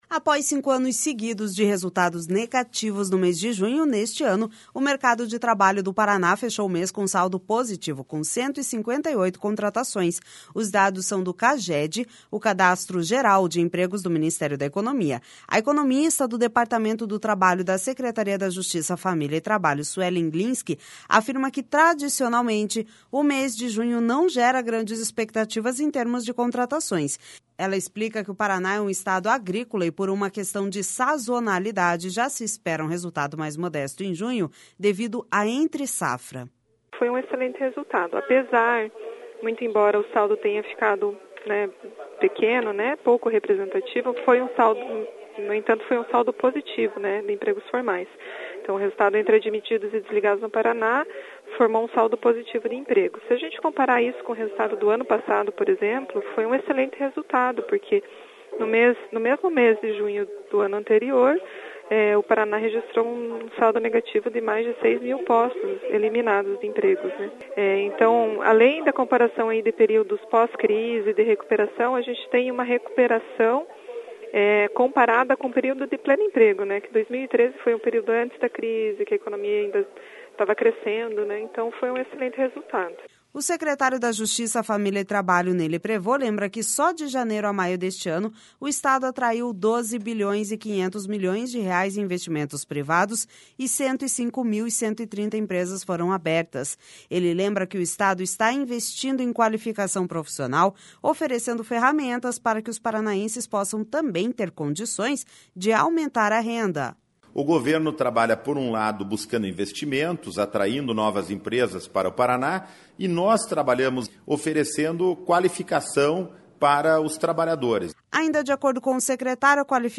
Ele lembra que o Estado está investindo em qualificação profissional, oferecendo ferramentas para que os paranaenses possam também ter condições de aumentar a renda.// SONORA NEY LEPREVOST//Ainda de acordo com o secretário, a qualificação do atendimento nas Agências do Trabalhador é um fator que ajuda os paranaenses a se colocarem mais rapidamente no mercado.